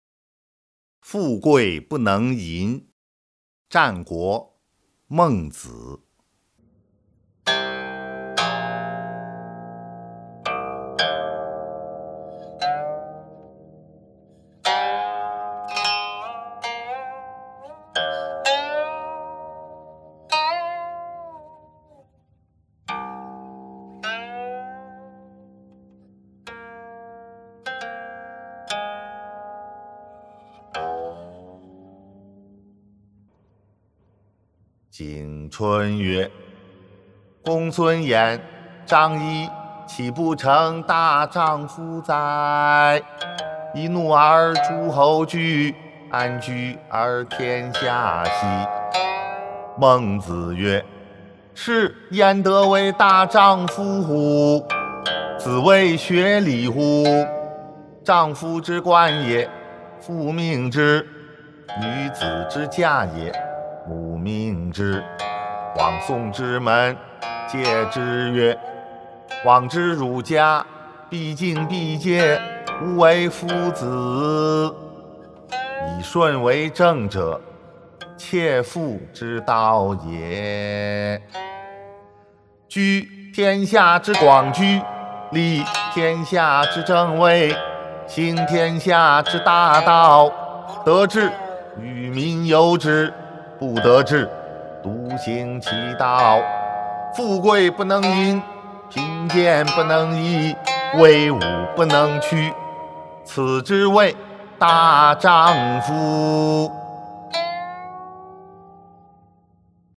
《孟子》富贵不能淫（吟咏）